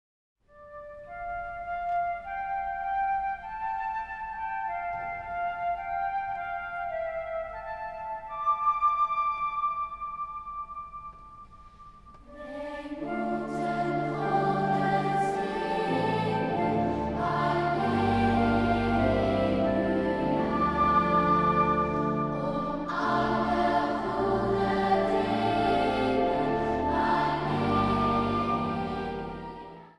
piano
orgel | trompet | gitaar | fluit | sopraan.
2 stemmen
Zang | Kinderkoor